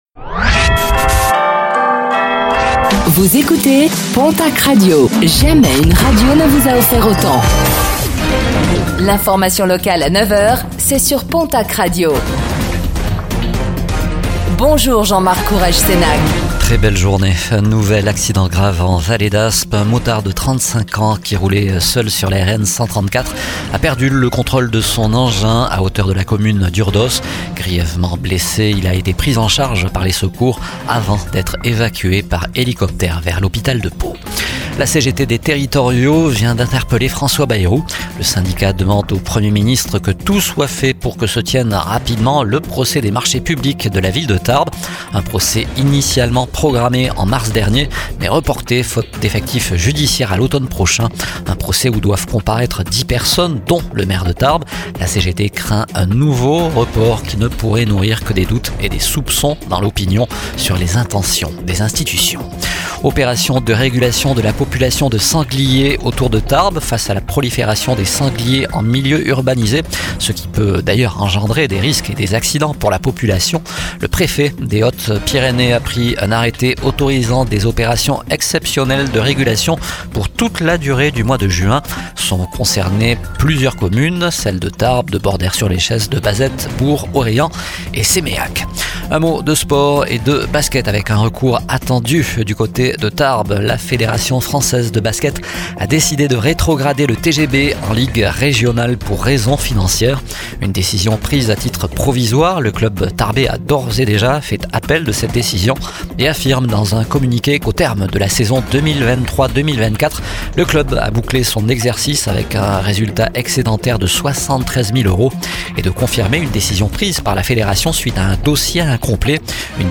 Infos | Mercredi 28 mai 2025